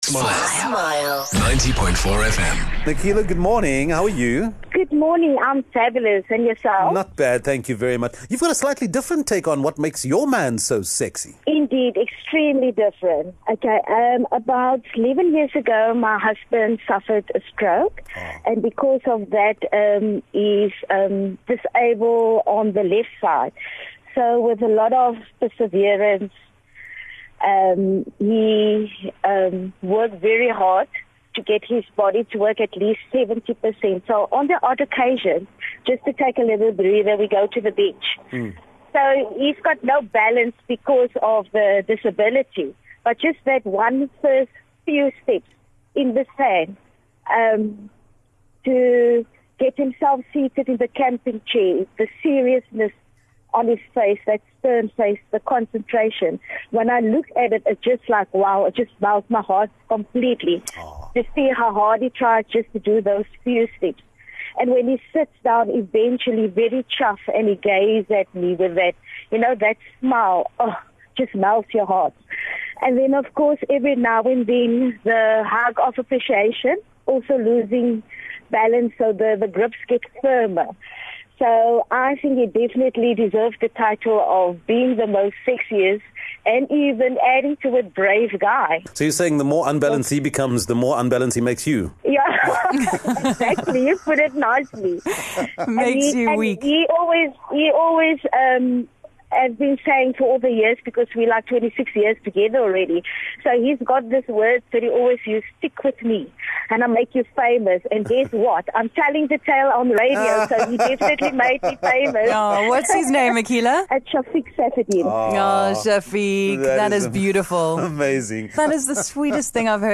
Smile Breakfast recently had a conversation about People magazine's sexiest man alive - Chris Evans. We asked listeners to tell us why they think the man in their life is actually the sexiest man alive.